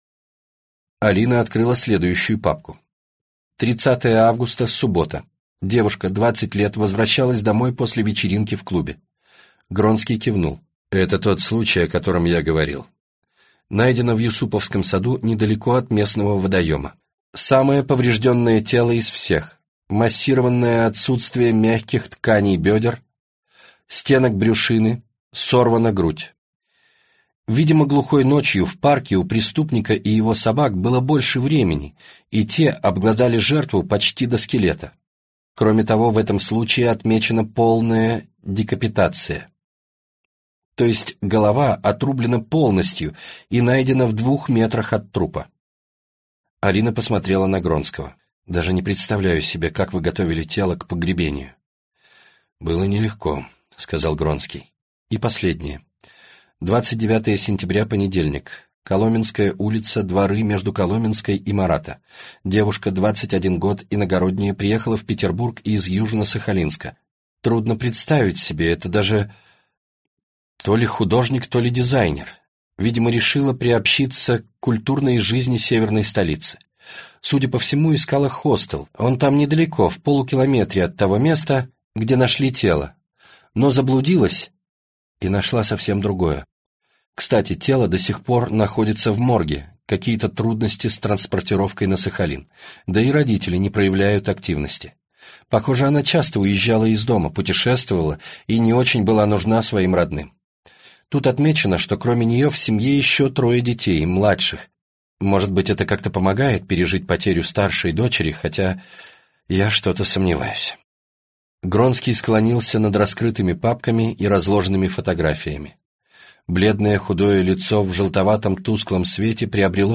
Аудиокнига Красные цепи - купить, скачать и слушать онлайн | КнигоПоиск